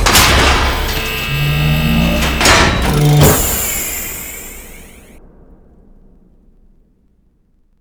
OpenAirlock.wav